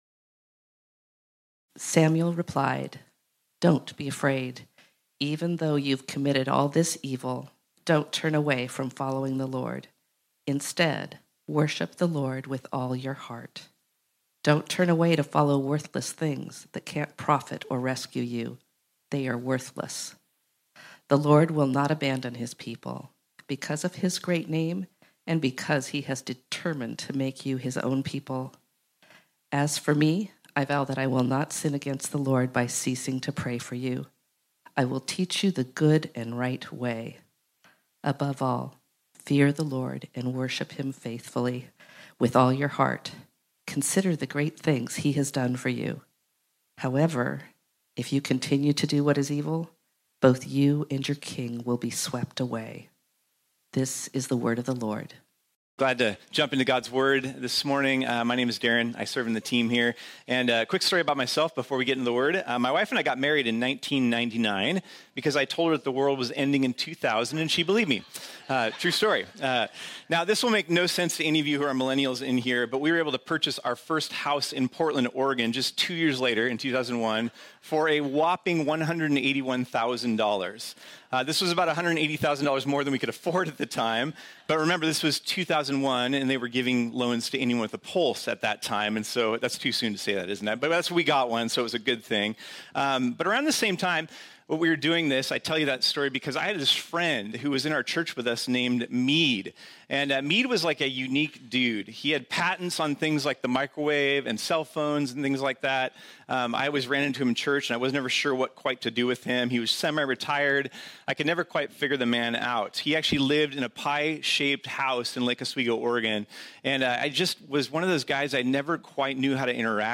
This sermon was originally preached on Sunday, May 4, 2025.